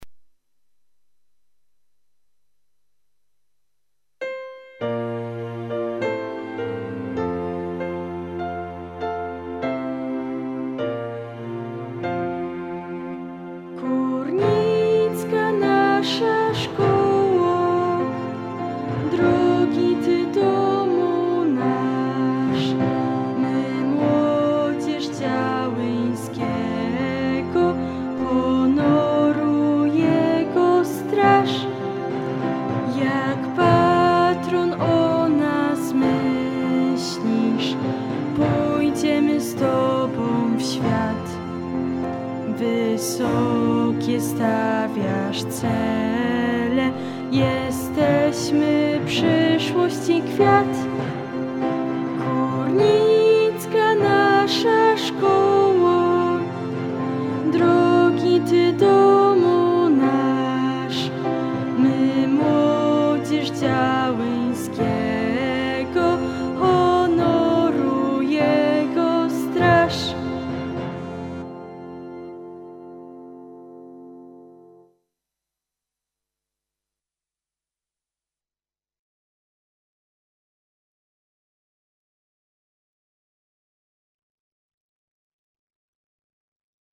HYMN-SZKOLY-Linia-Melodyczna.mp3